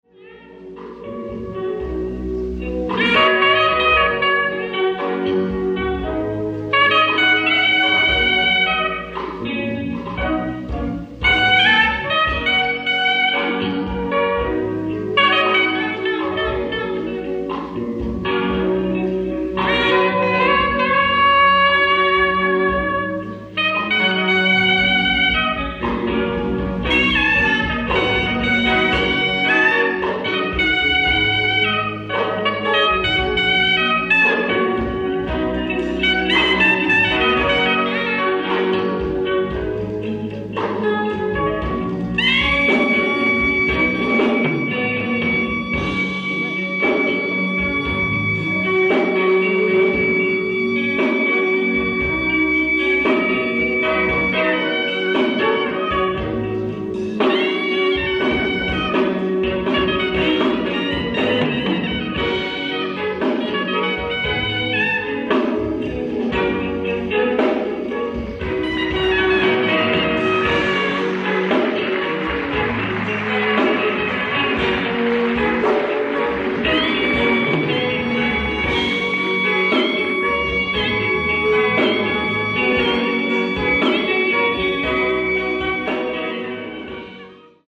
ライブ・アット・スメラルド劇場、ミラン、イタリア 03/11/1991
※試聴用に実際より音質を落としています。